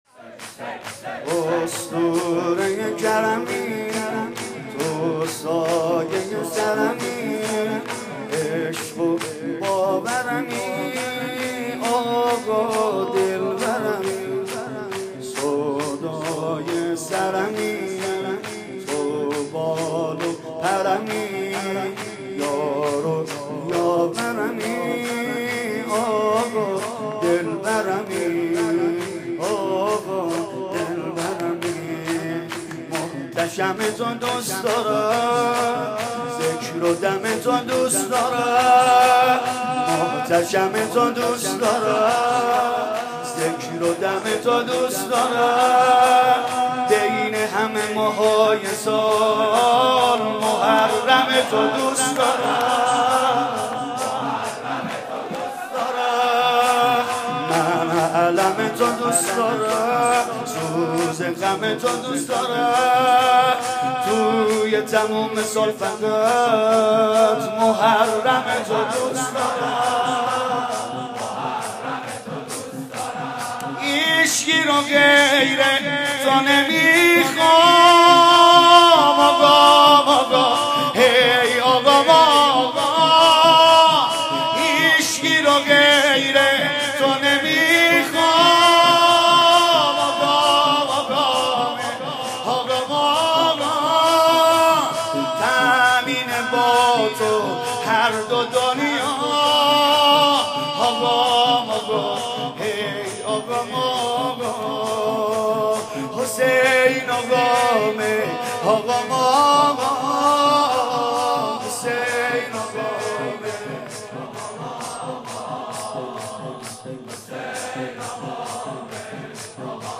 «مداحی ویژه اربعین 1394» شور: من اربعین کربلا میخوام